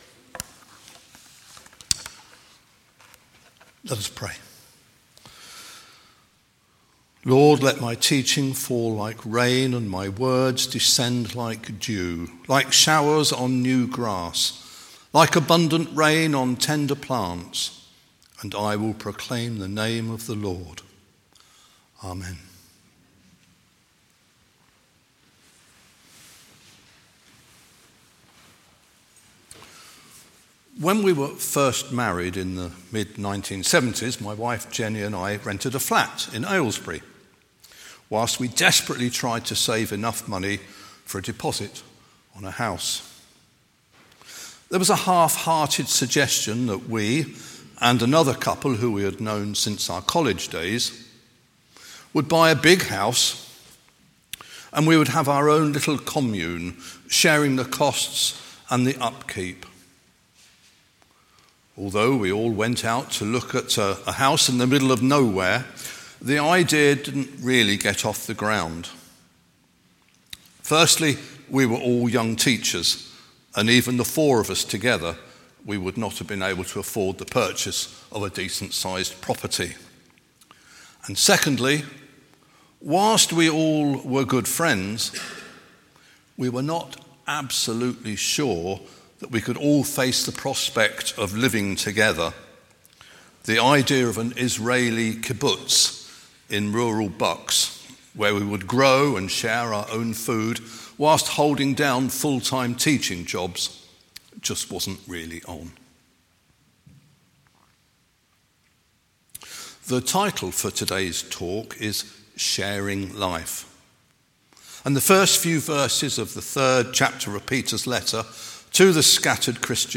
Kingdom of God sermons | Wychert Vale Benefice